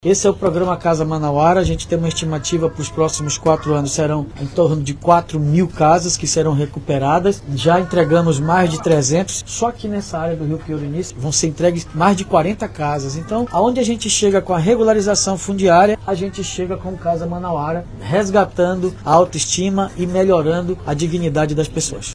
O programa Casa Manauara tem como objetivo realizar reparos estruturais essenciais nas casas de moradores que enfrentam dificuldades financeiras. As reformas incluem a troca de telhados, forros, parte elétrica, reboco, pintura e até a construção de banheiros, de acordo com a necessidade de cada residência, como explica o prefeito David Almeida.